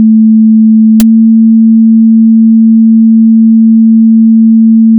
So I simulated a 220Hz tone (the frequency of a starling’s call, roughly) and applied this “flinch coefficient” to it. I forced the sound to change pitch, but I made it drag.
Listen to the artifacts. The crackle. The way it refuses to settle.
That noise? That isn’t a glitch.